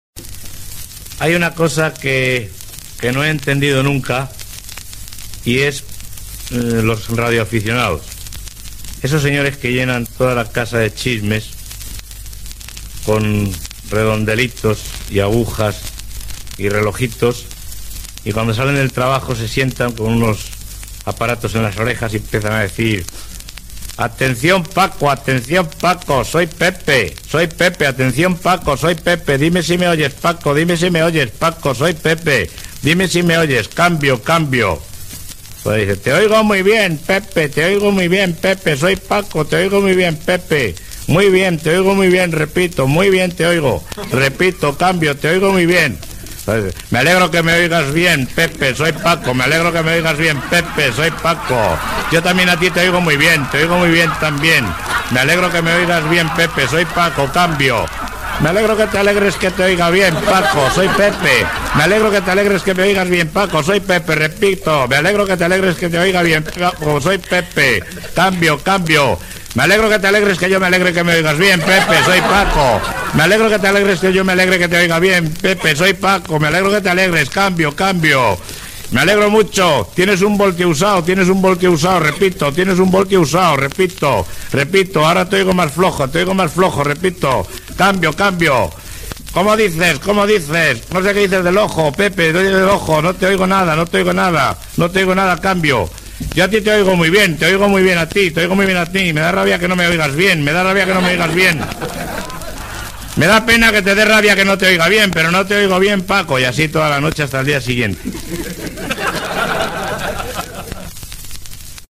Humor